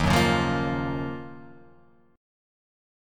Listen to D#m9 strummed